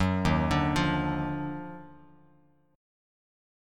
D#mM9 chord